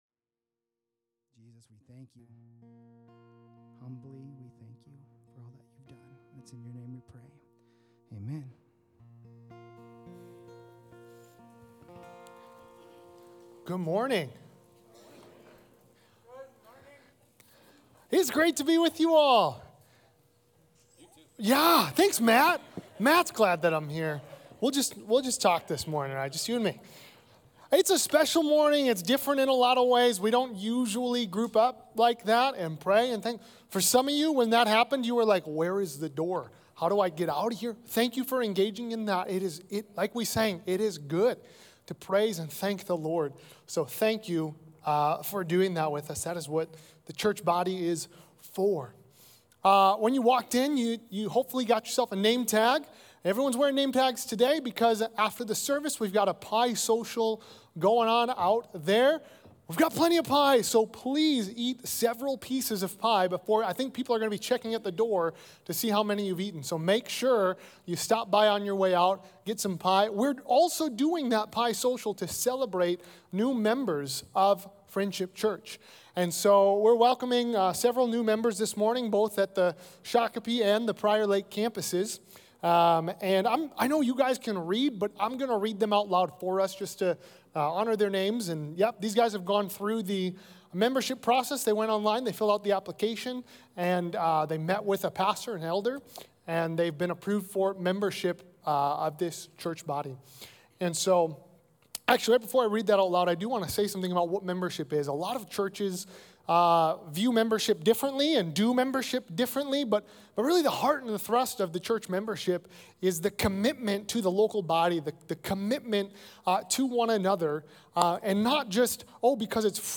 Listen to the Sunday morning messages given by the pastors of Friendship Church, Prior Lake and Shakopee, Minnesota.